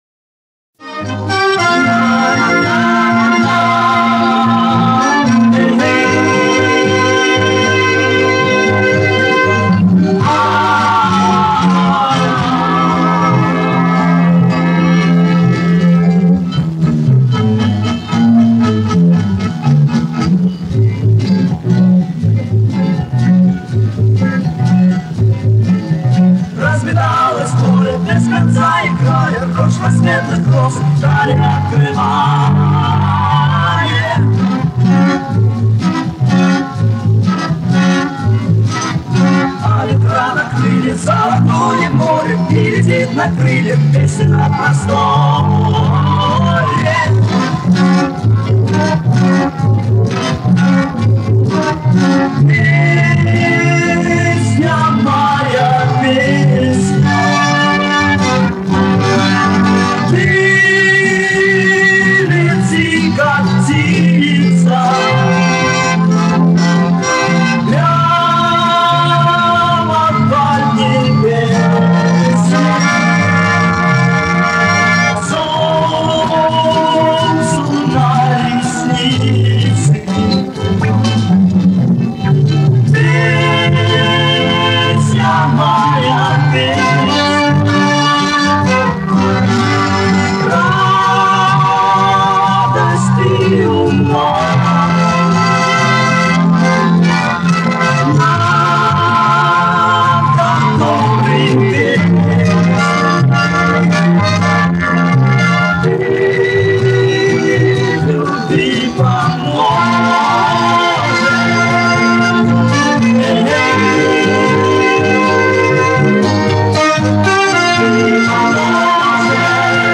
Запись с очень большим перегрузом.